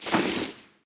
equip_chain4.ogg